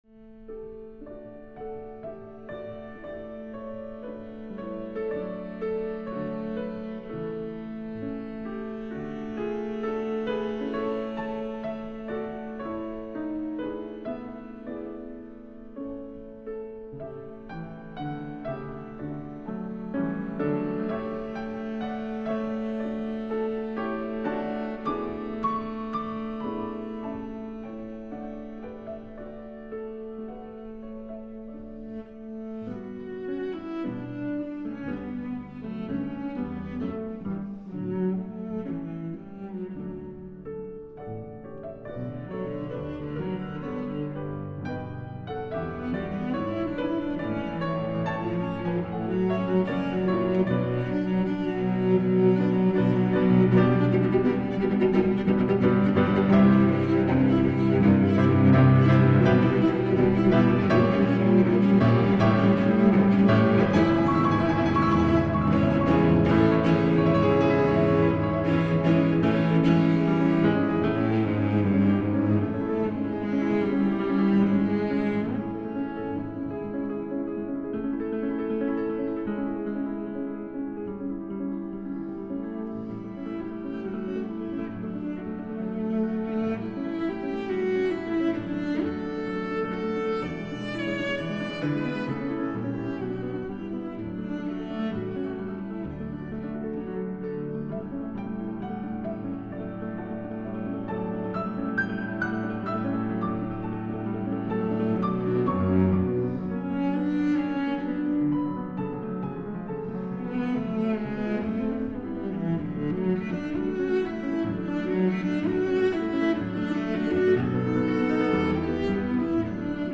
Violoncellista che si esibirà con Krzysztof Urbański
Incontro con Sol Gabetta